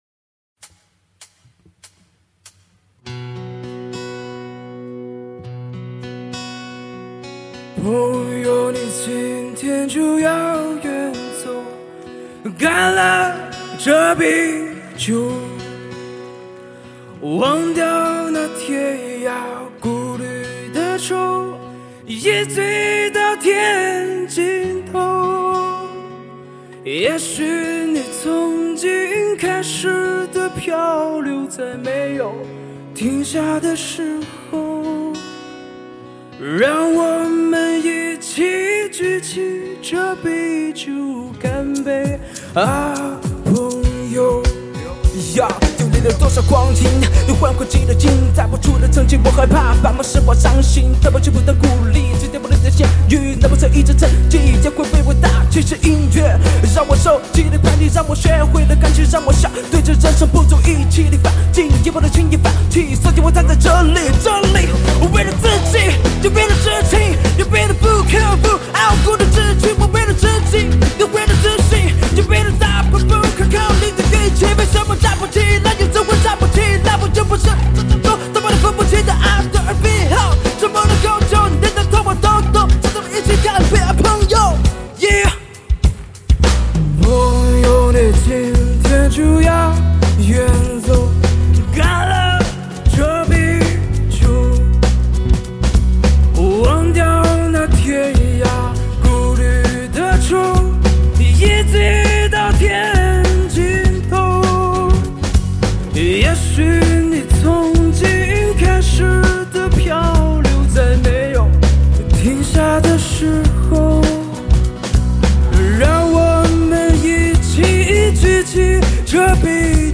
自然无造作真实原音再现，无与伦比的震撼和感动。
原生态、丝般魅力、清凉脱俗、独特醇厚集聚一体。